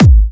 VEC3 Bassdrums Trance 73.wav